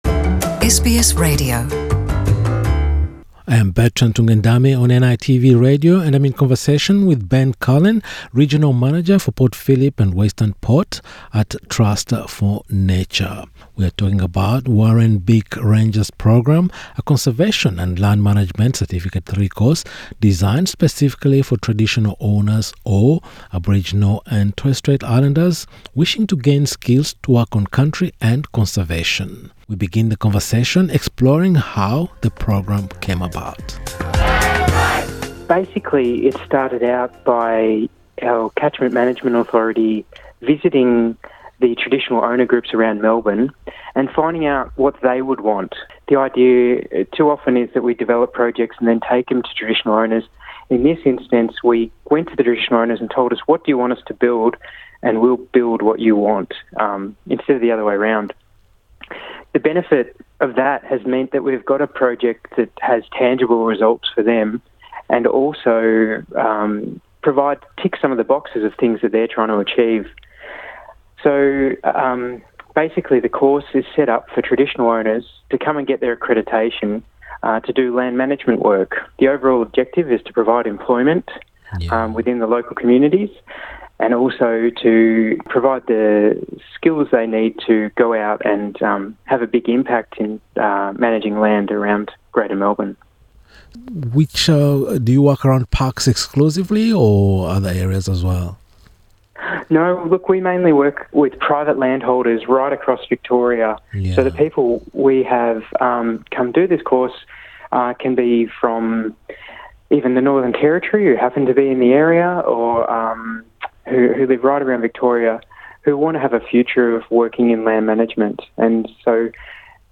In a conversation with NITV Radio